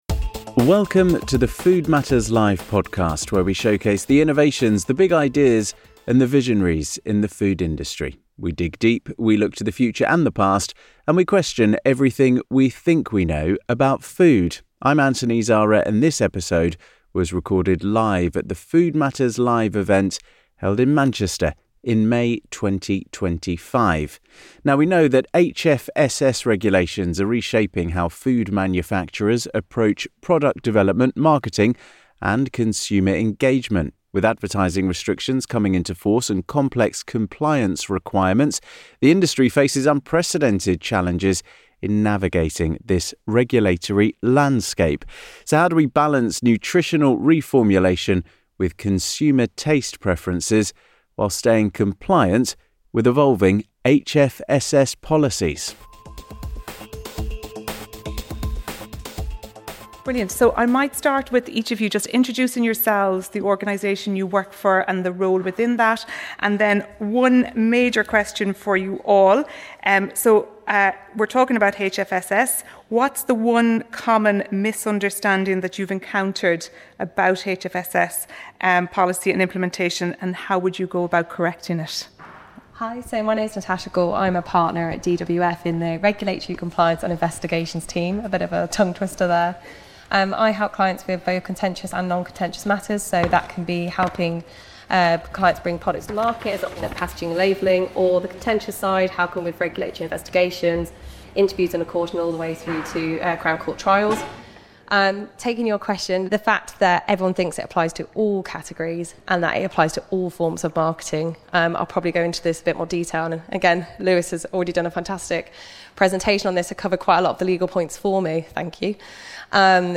Are panel of experts discuss reformulation and innovation challenges, legal compliance and enforcement, and how different stakeholders are working together to navigate HFSS requirements.